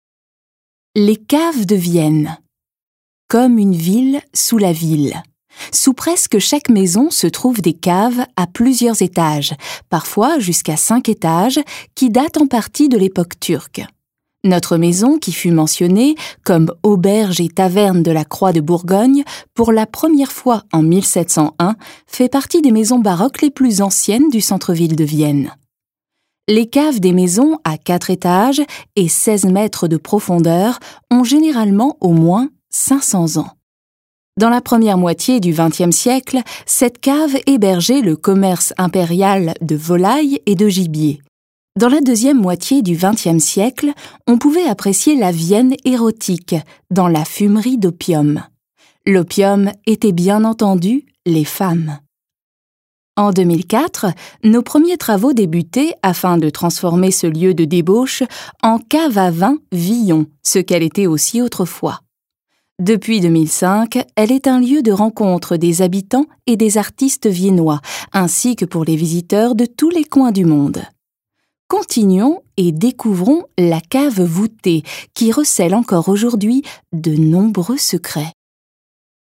Villon_Audioguide_FR_1_Eingang_0817.mp3